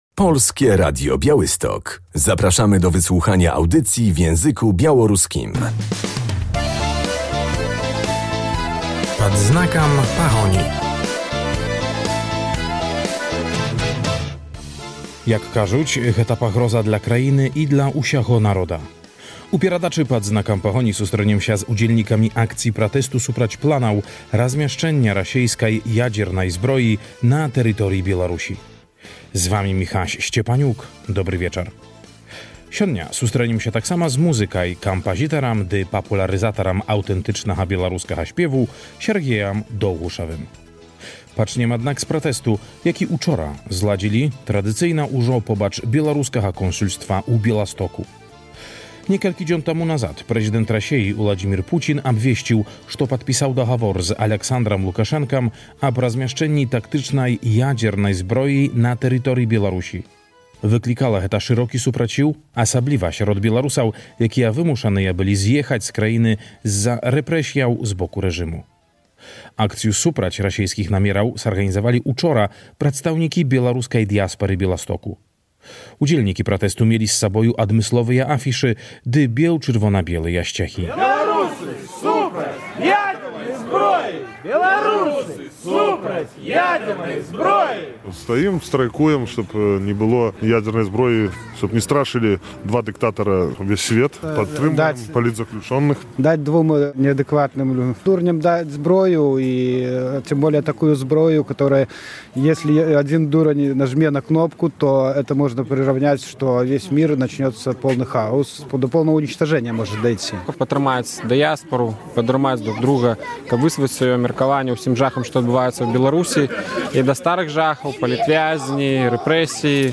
Pikieta przeciw broni atomowej w Białorusi 3.04.2023